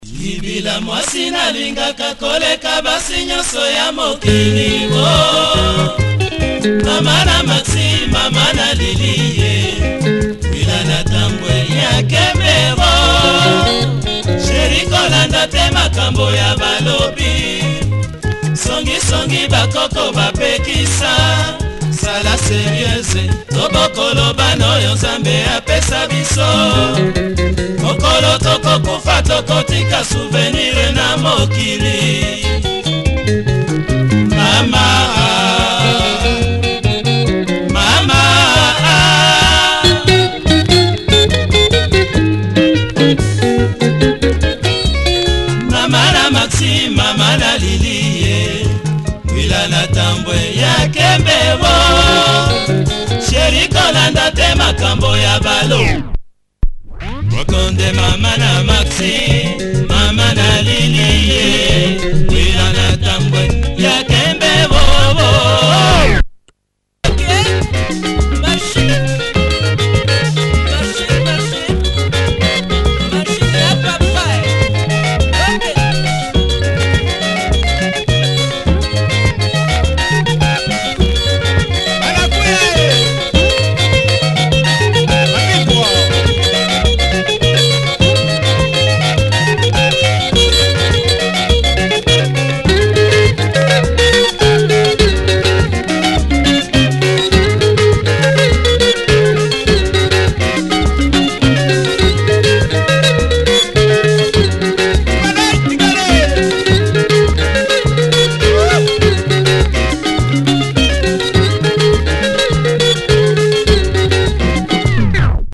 Nice lingala track.